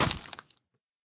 sounds / mob / zombie / step2.ogg
step2.ogg